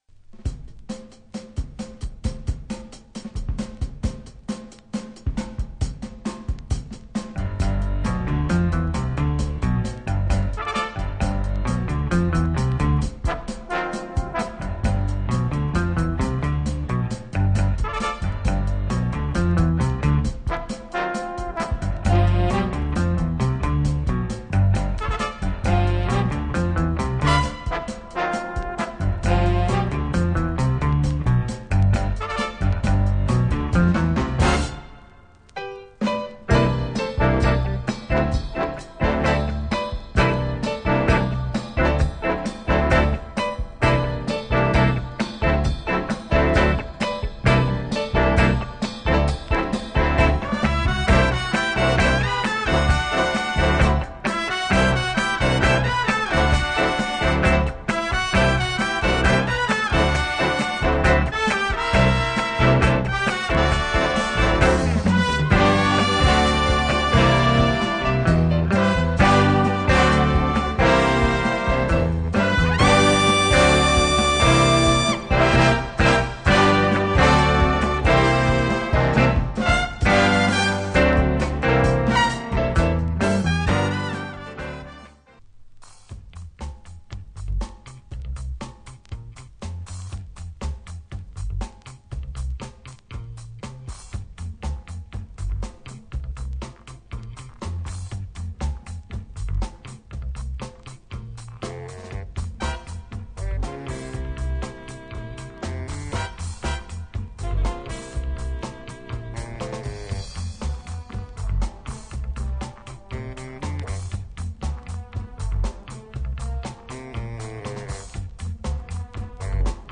Obscure Baltic jazz with some groovy intentions.